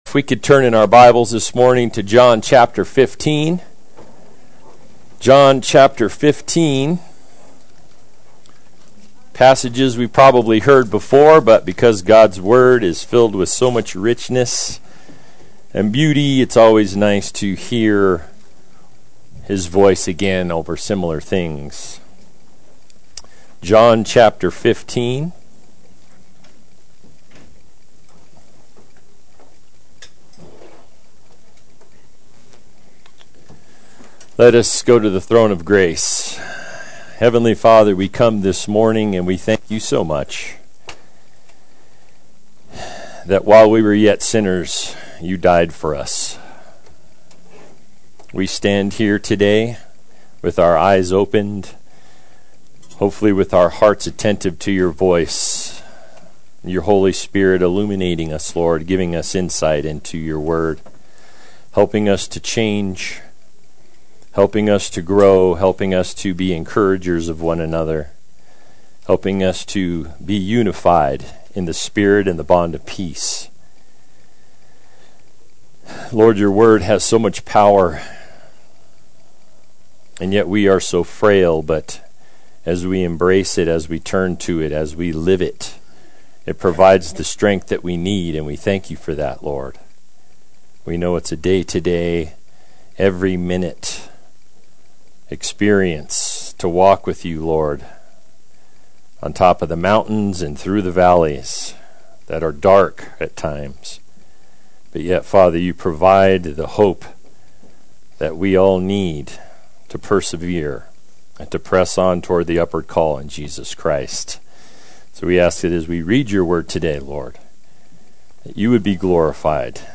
Play Sermon Get HCF Teaching Automatically.
The World Hates the Disciples Adult Sunday School